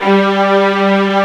Index of /90_sSampleCDs/Roland L-CD702/VOL-1/CMB_Combos 2/CMB_Bryt Strings